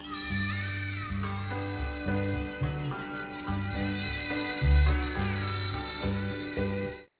A cross between a harmonica and a fiddle. By using his/her mouth as a resonator, the musician can inflect or "pronounce" each note.